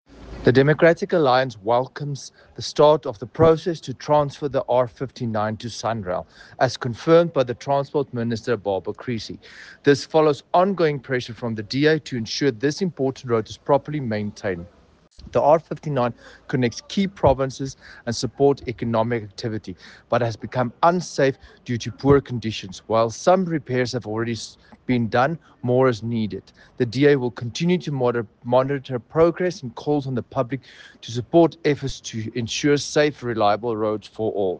Afrikaans soundbites by Dr Igor Scheurkogel MP and